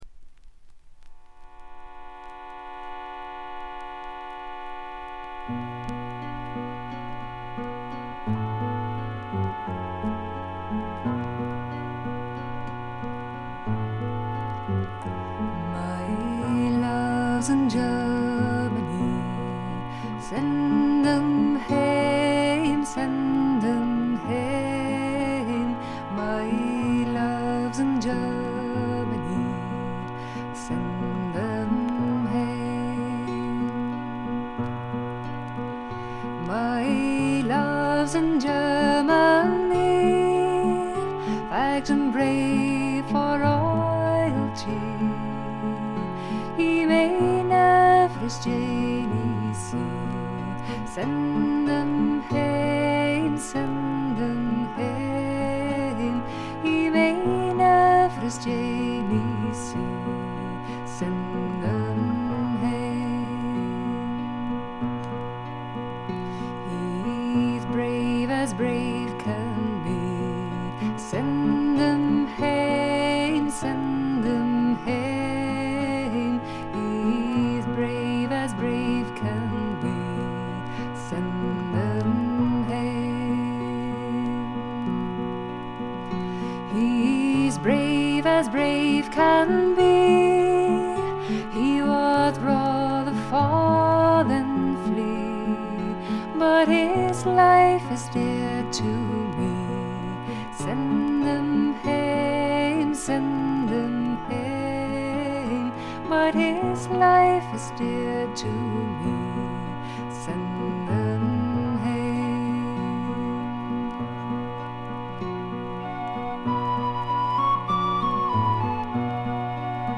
バックグラウンドノイズ、チリプチ多め大きめ。
オランダのトラッド・フォーク・グループ
試聴曲は現品からの取り込み音源です。